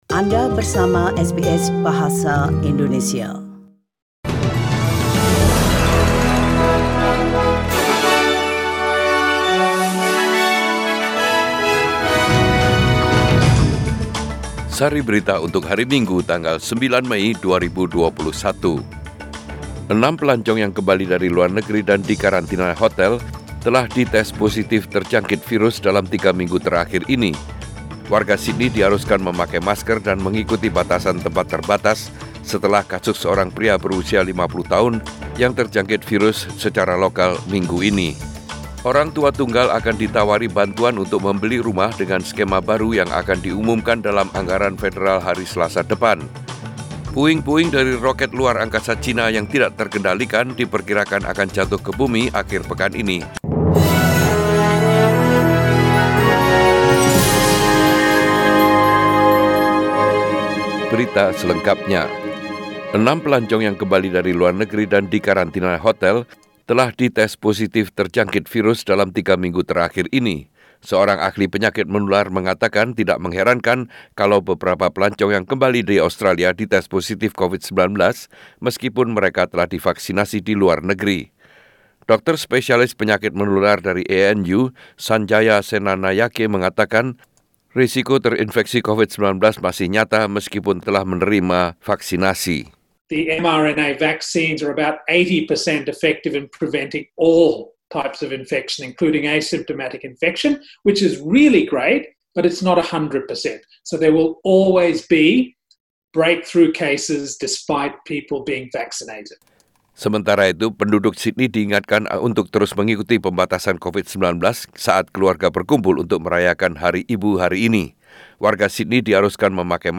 SBS Radio News in Bahasa Indonesia - 9 May 2021